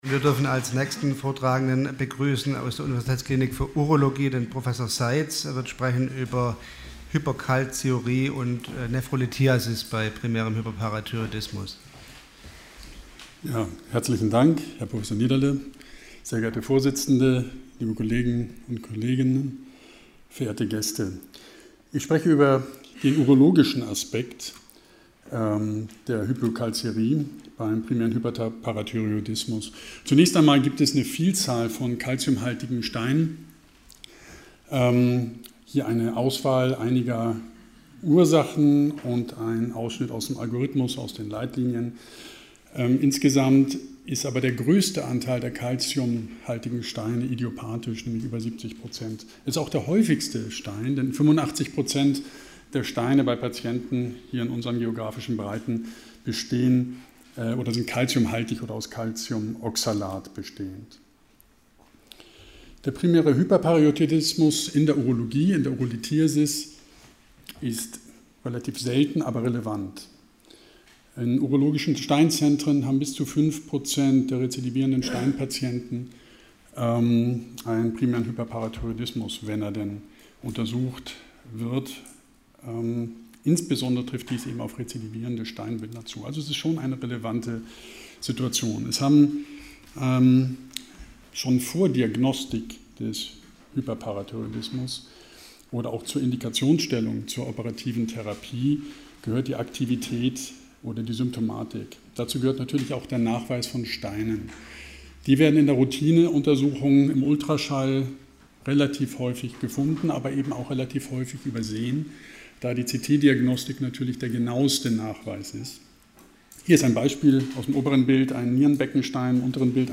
Sie haben den Vortrag noch nicht angesehen oder den Test negativ beendet.